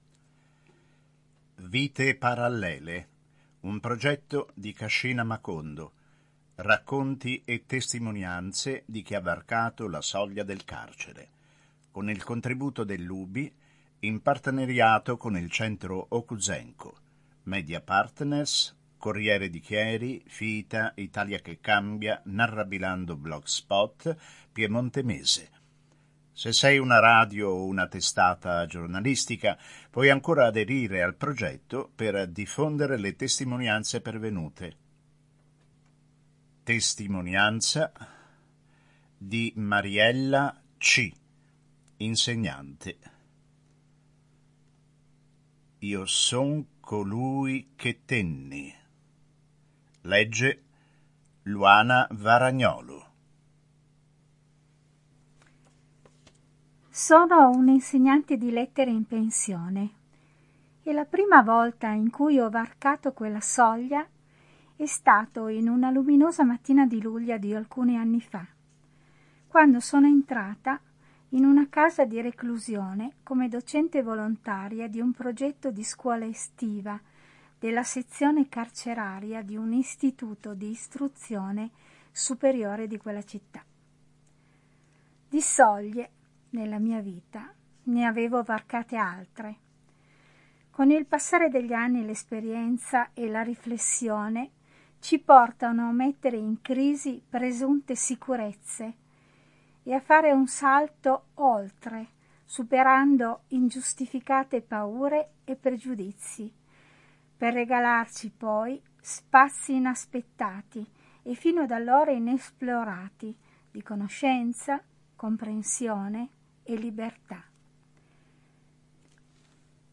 il file audio, con testo registrato, in formato mp3.
TESTIMONIANZA N° 12